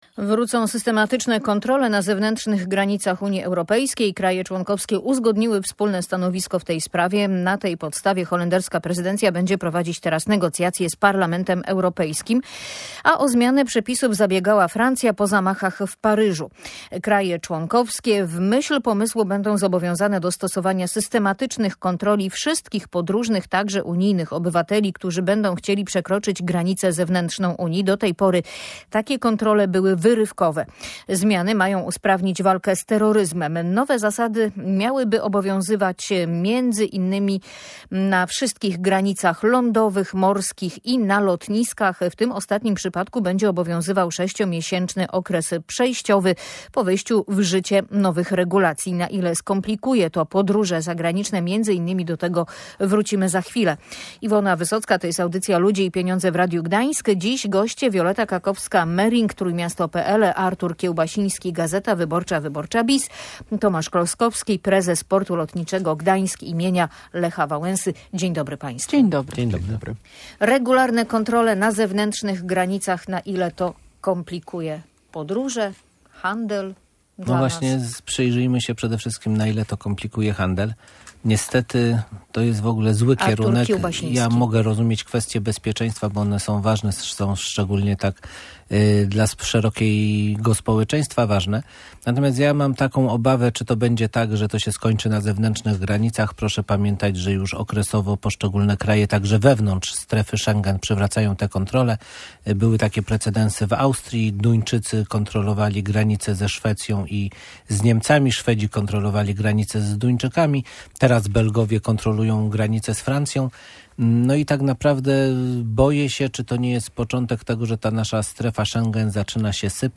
O zmianach na granicach rozmawiali goście audycji Ludzie i Pieniądze: